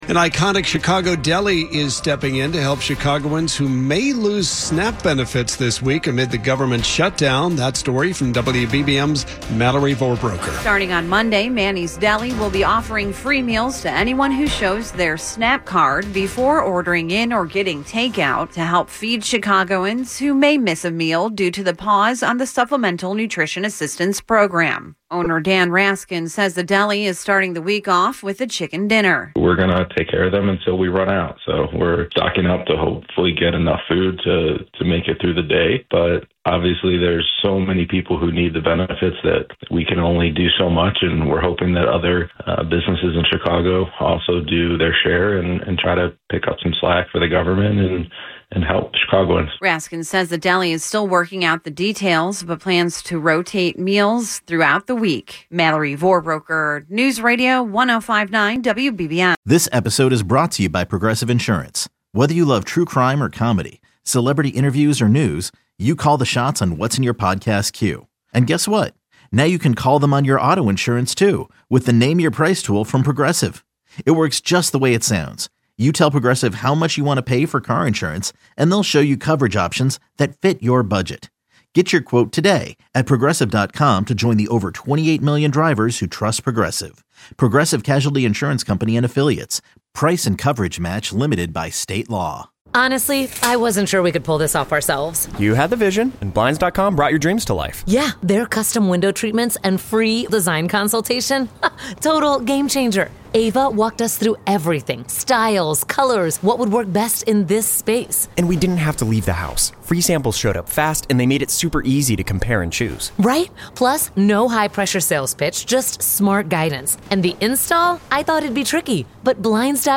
A dive into the top headlines in Chicago, delivering the news you need in 10 minutes or less multiple times a day from WBBM Newsradio.